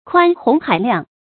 宽洪海量 kuān hóng hǎi liàng
宽洪海量发音